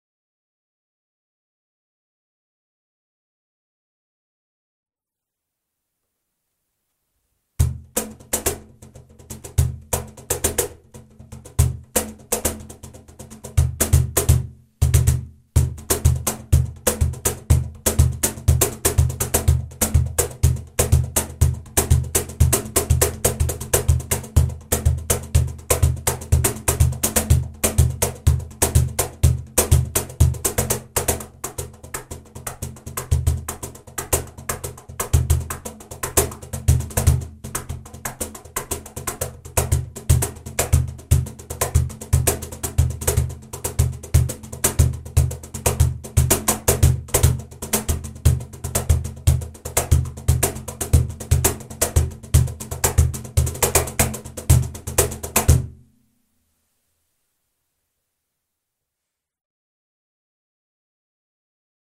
Cajon model Alhambra Studio série Super Bass
Cajóny série Studio jsou navrženy tak, aby nabízely jasně artikulovaný a úderný rytmický zvuk, přičemž neztrácejí teplý a příjemný tón s širokým dynamickým rozsahem.
Navrženo pro maximalizaci basového tónu
Zvukový test
Struný: 6 struny namontované do tvaru “V”
SUPER-BASS.mp3